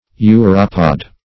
Search Result for " uropod" : The Collaborative International Dictionary of English v.0.48: Uropod \U"ro*pod\, n. [2d uro- + -pod.]